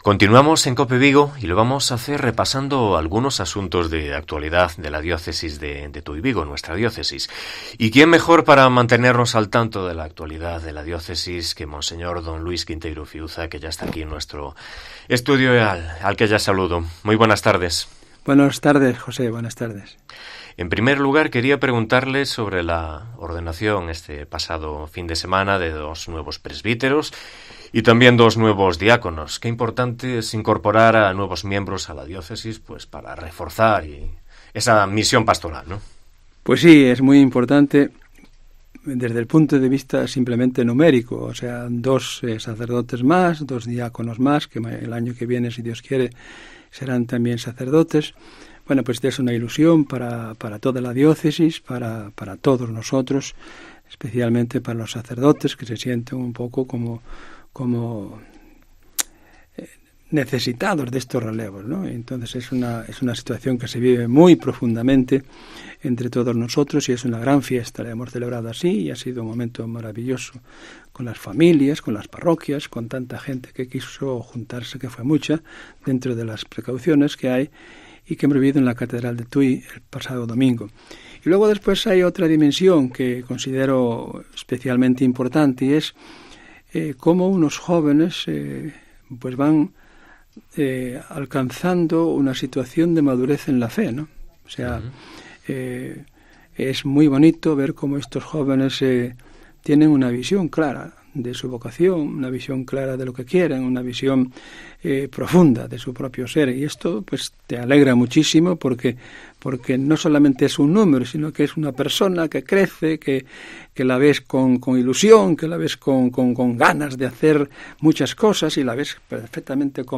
Entrevista a Monseñor D. Luis Quinteiro Fiuza, obispo de Tui-Vigo